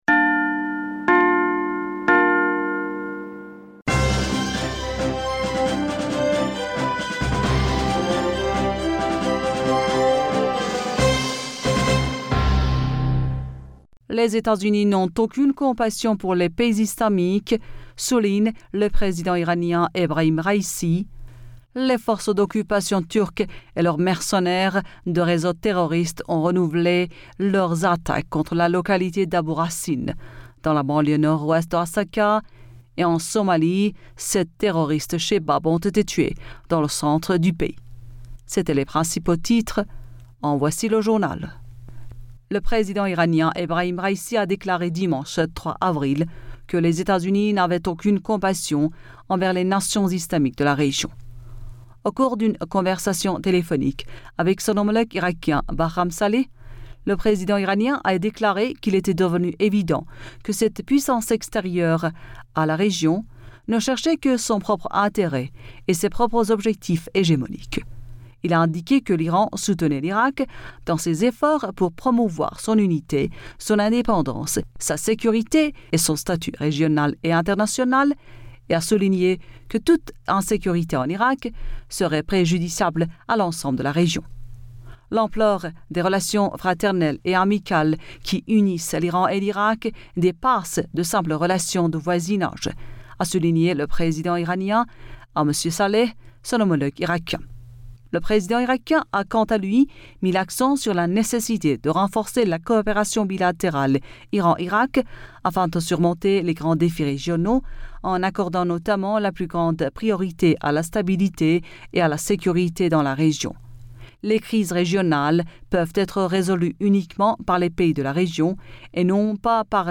Bulletin d'information Du 04 Avril 2022